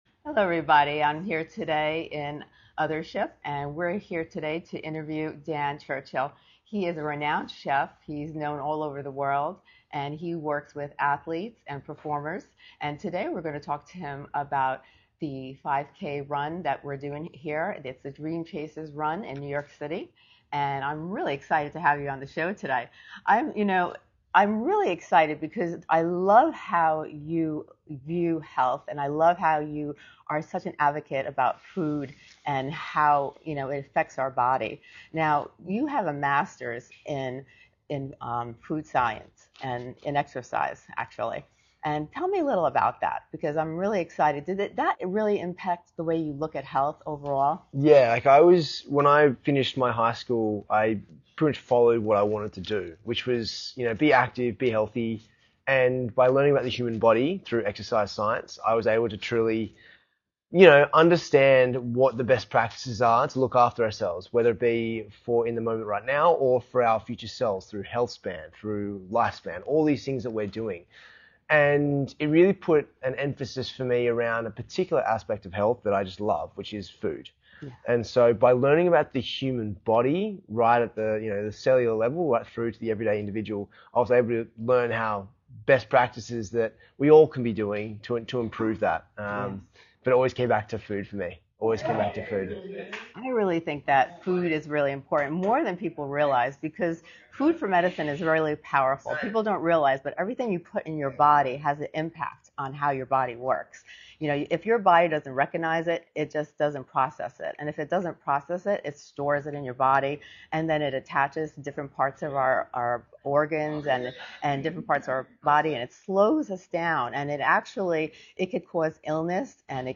Recorded live at Hone Health’s Dream Chasers Run Event in NYC, Dan opens up about his journey from Sydney to the global stage, what it really takes to build lasting discipline, and how the right mindset (and meal!) can completely transform your performance and purpose.